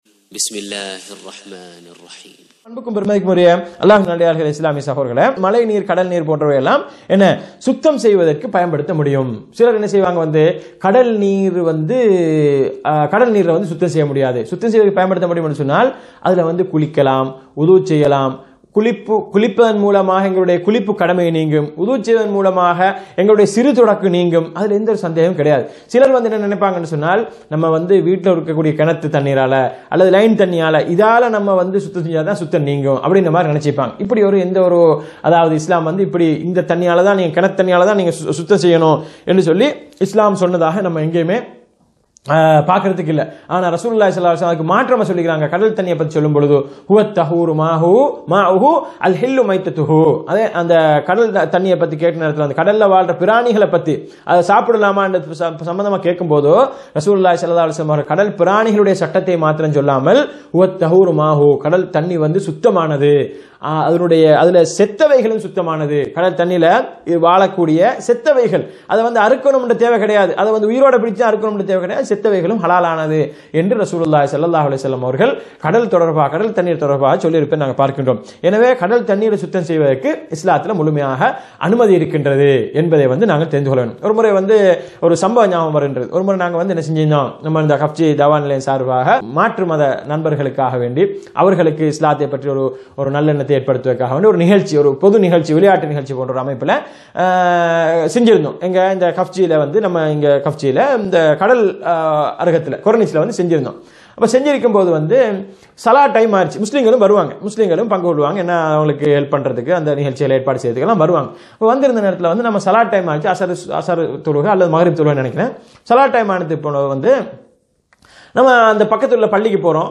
சுத்தம், அசுத்தம் பற்றிய பாடங்களின் விளக்கங்களிலிருந்து…